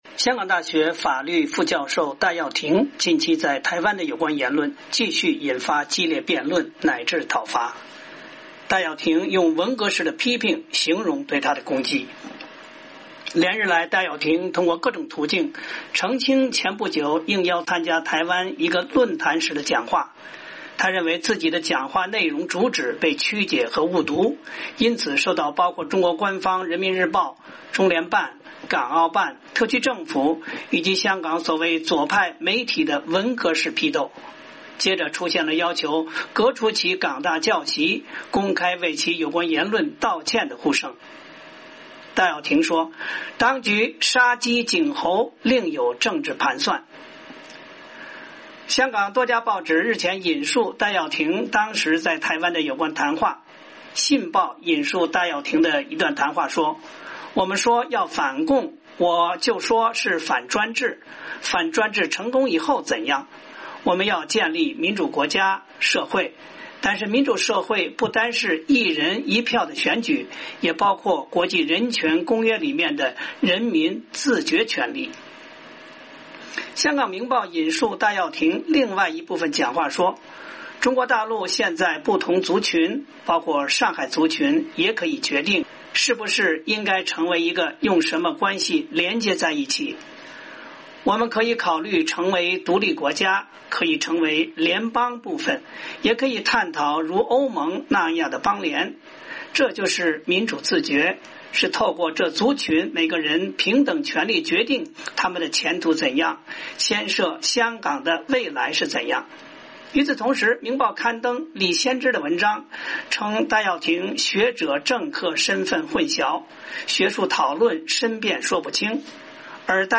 美国之音星期二晚上采访了戴耀廷教授。